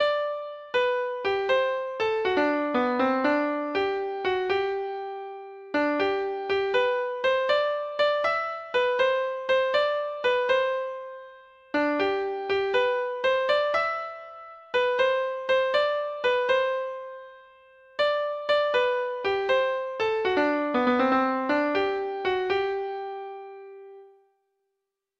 Traditional Trad. The Maid on the Mountain's Brow Treble Clef Instrument version
Traditional Music of unknown author.